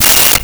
Cell Phone Ring 13
Cell Phone Ring 13.wav